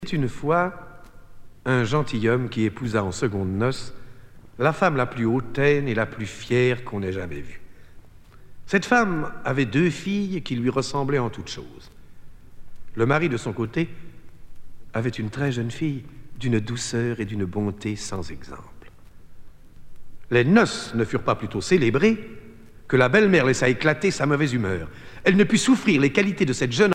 Contes et légendes chantés
Genre conte